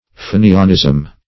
Fenianism \Fe"ni*an*ism\, n. The principles, purposes, and methods of the Fenians.